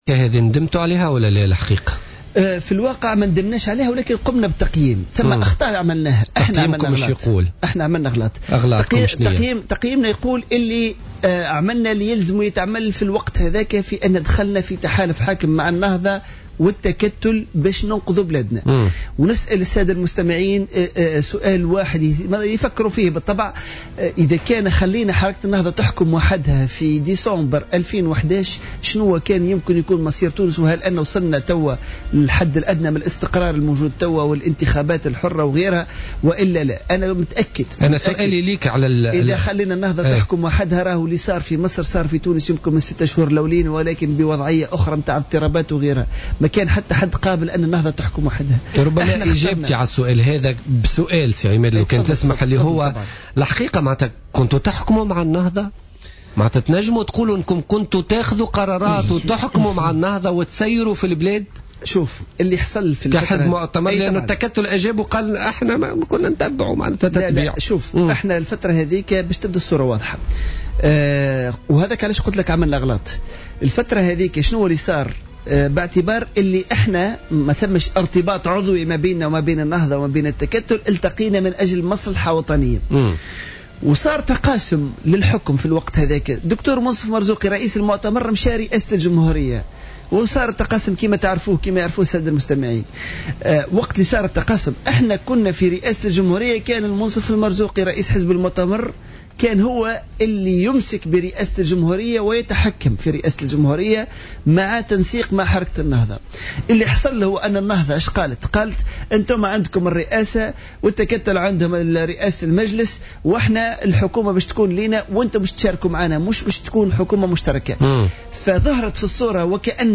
قال نائب مجلس الشعب عن حزب المؤتمر من أجل الجمهورية عماد الدايمي ضيف برنامج "بوليتيكا" اليوم الثلاثاء إن حزبه قد ارتكب بعض الأخطاء خلال مشاركته في الحكم.